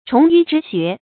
虫鱼之学 chóng yù zhī xué
虫鱼之学发音